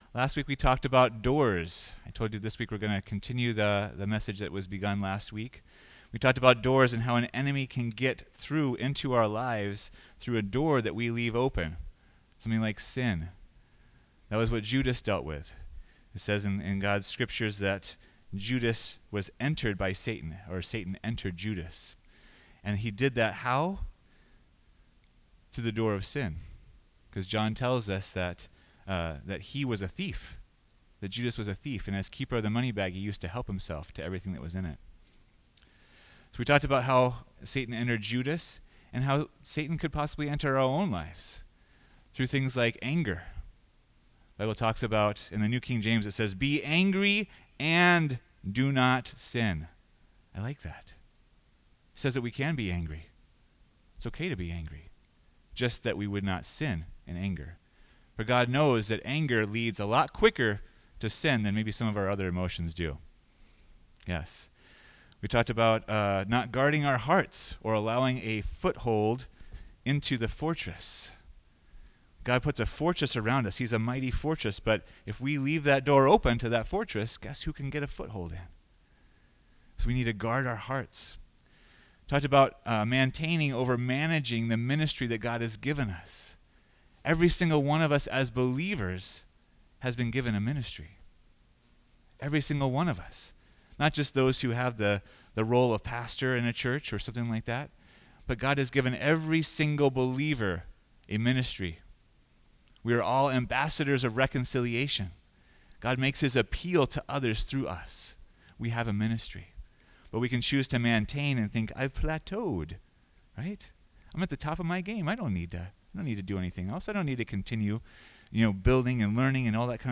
Stand Firm and You Will Win Life Preacher